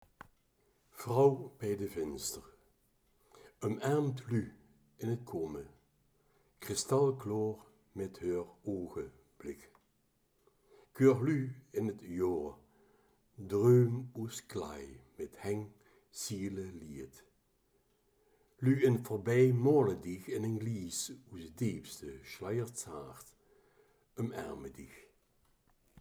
Vrauw bij de vinster | Kerkraads Dialekt